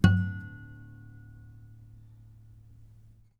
strings_harmonics
harmonic-08.wav